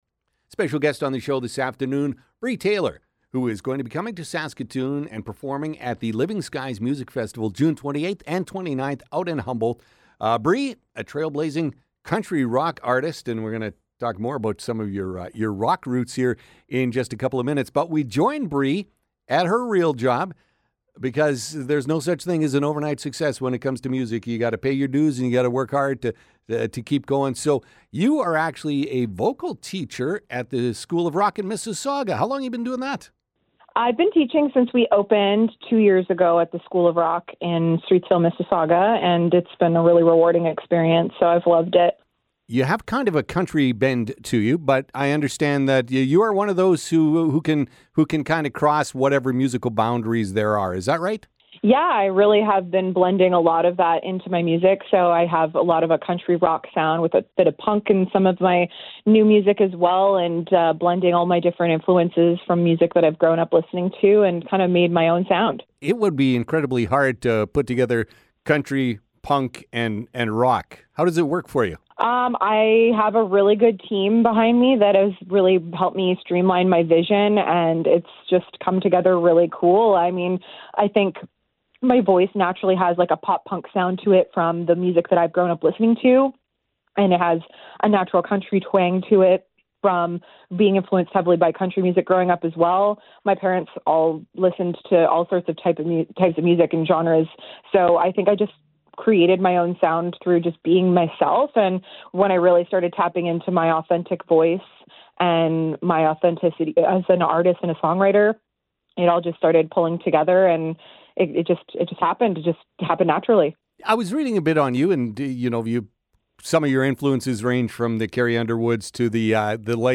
A trailblazing country rock artist who is definitely a rising star in the music industry.